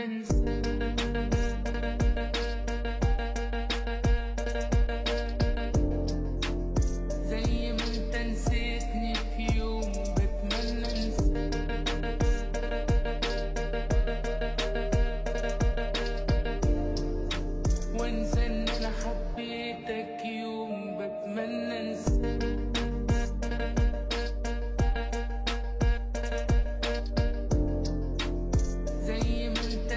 Egyptian Pop Pop Hip-Hop Rap Egyptian Hip-Hop
Жанр: Хип-Хоп / Рэп / Поп музыка